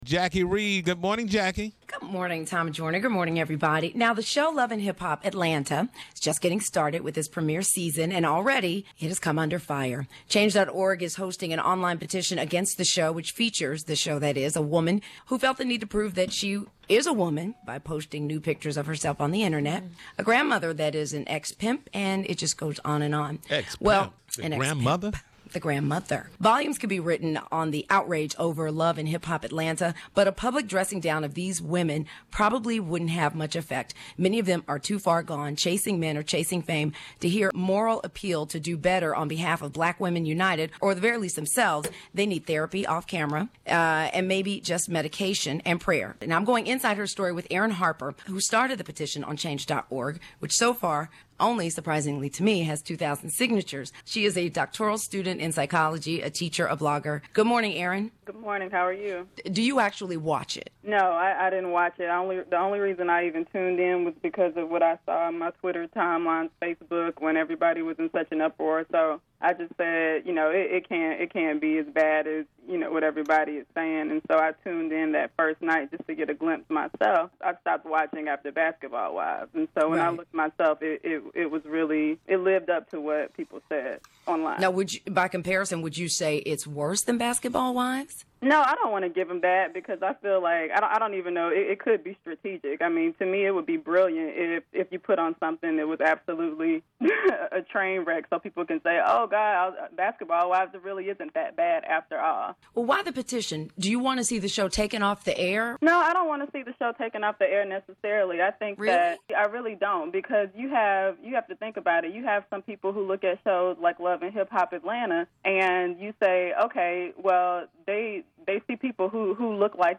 Exclusive Interviews
Do you think shows like ‘Love and Hip Hop Atlanta’  and all of the other reality tv shows depict our black women in a negative light and do you think the younger generation has faltered because of shows like this?  Check out the interview from this morning on the TJMS.